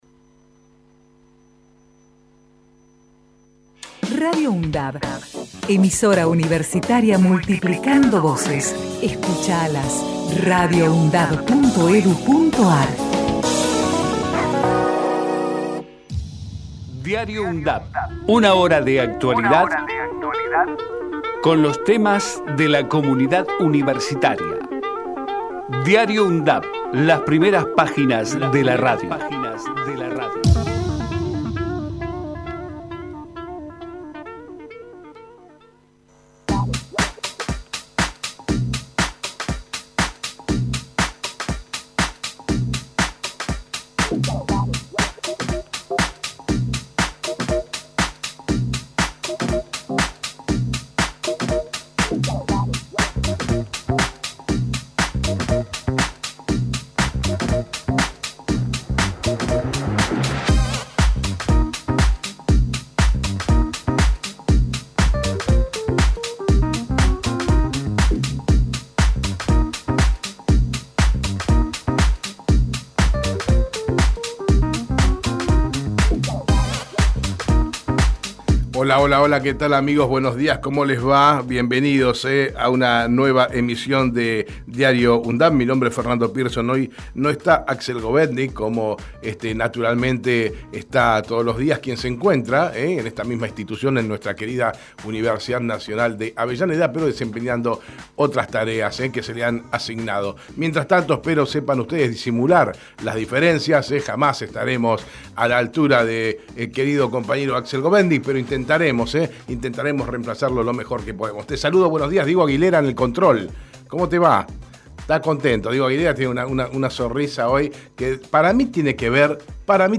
De lunes a viernes de 9 a 10 realizamos un repaso por la actualidad universitaria en las voces de los protagonistas, testimonios de quienes forman parte de la UNDAV. Investigamos la historia de las Universidades Nacionales de todo el país y compartimos entrevistas realizadas a referentes sociales, culturales y académicos. Todos los días, cerramos Diario UNDAV, con nuestro ciclo de efemérides musicales, histórico y variado.